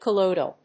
音節col・loi・dal 発音記号・読み方
/kəlˈɔɪdl(米国英語)/